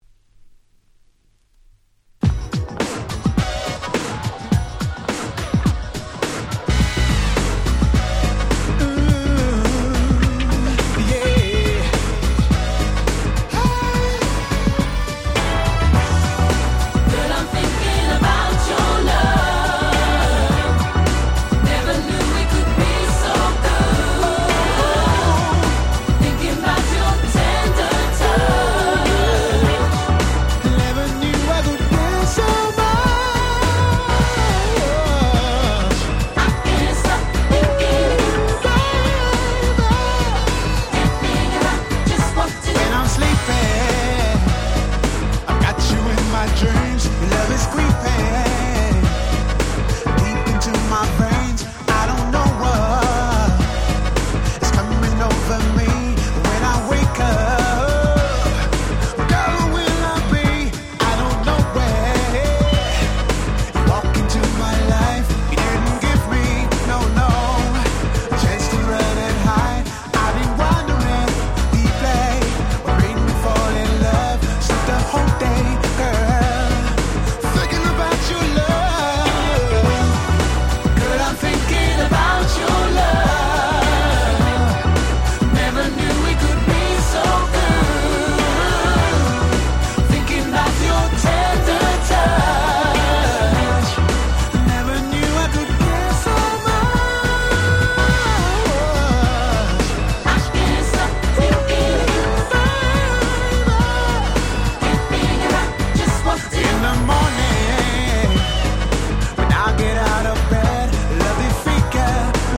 Super Nice UK R&B !!